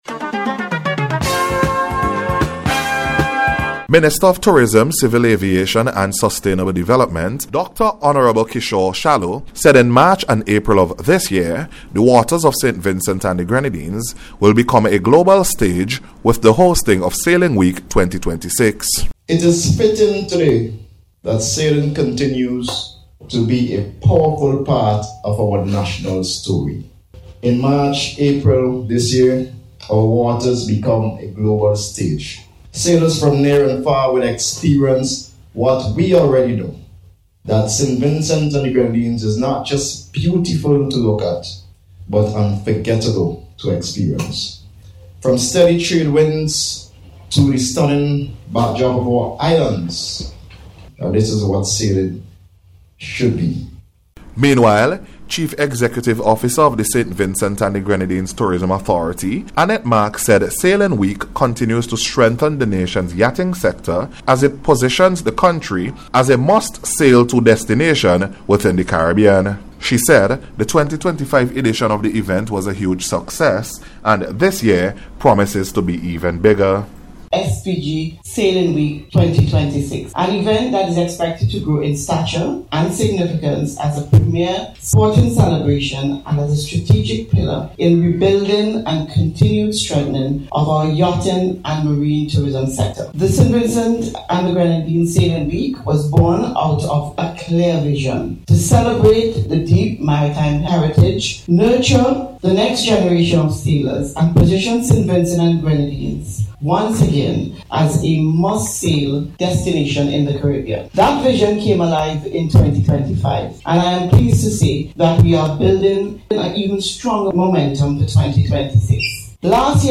NBC’s Special Report- Tuesday 13th January,2025